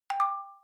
message_tune.mp3